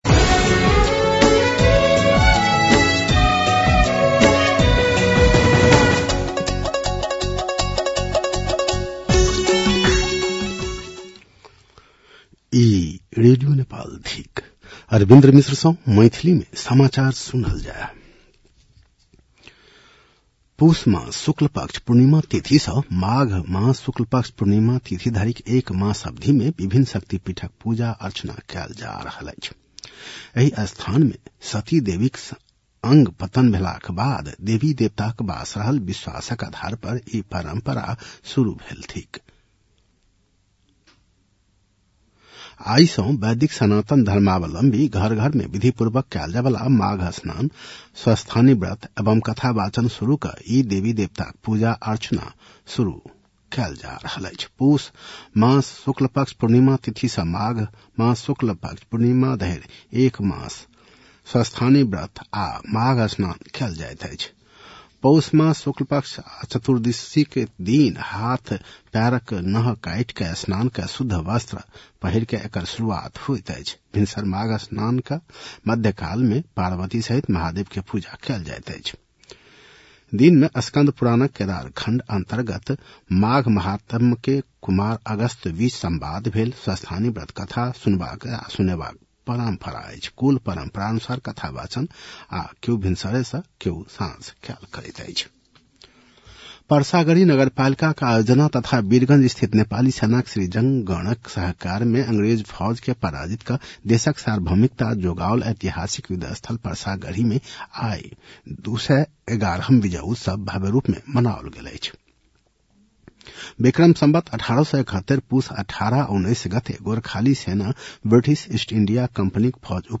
मैथिली भाषामा समाचार : १९ पुष , २०८२